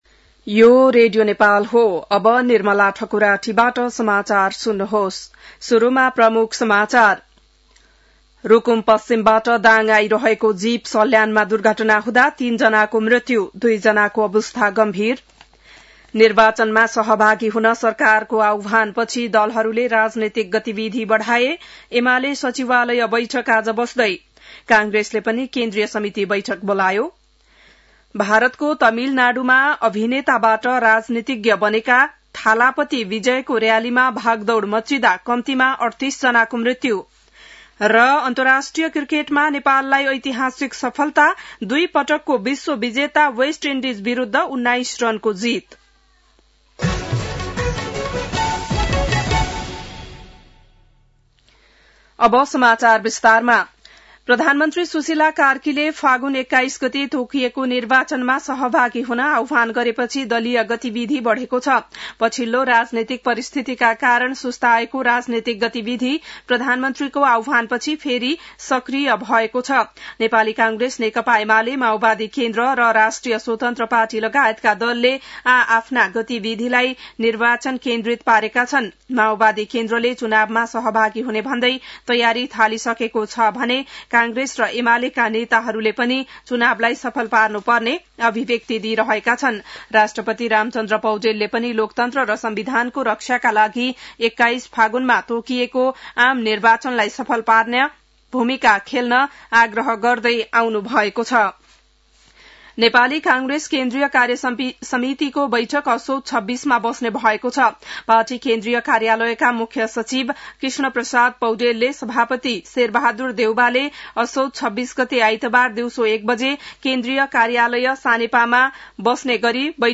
बिहान ९ बजेको नेपाली समाचार : १२ असोज , २०८२